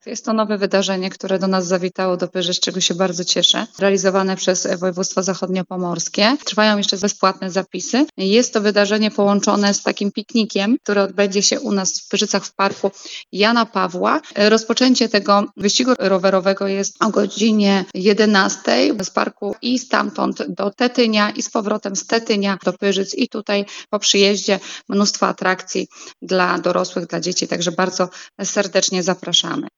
Rozpoczęcie wyścigu rowerowego jest o godzinie 11:00 z parku i stamtąd do Tetynia i z powrotem do Pyrzyc i po przyjeździe mnóstwo atrakcji dla dorosłych, dla dzieci zaprasza Marzena Podzińska, burmistrz Pyrzyc